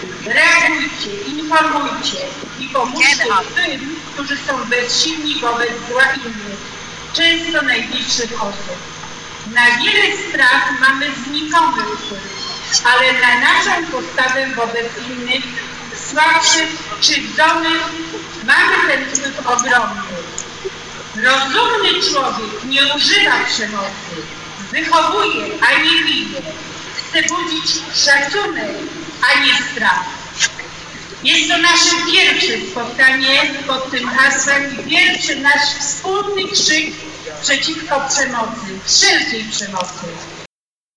Dziś w całym powiecie żnińskim odbywały się happeningi 'Dzieci przeciwko przemocy”.
Przemówienie